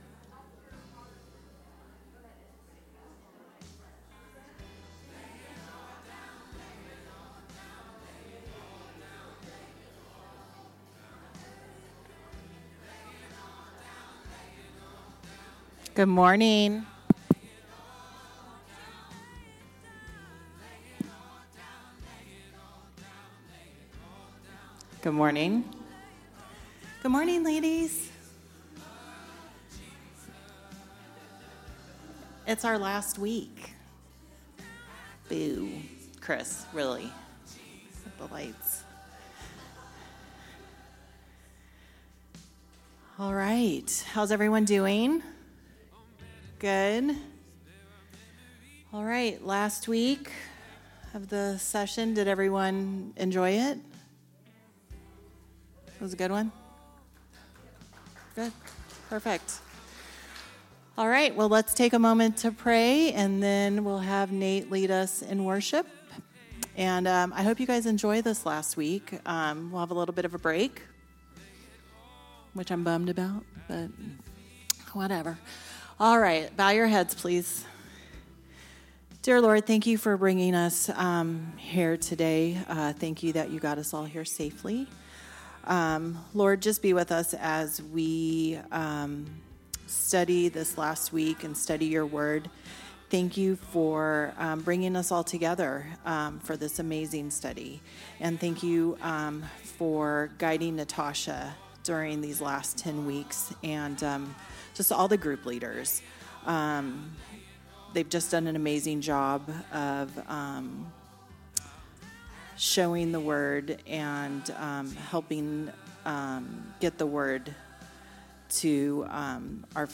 Week 10 Message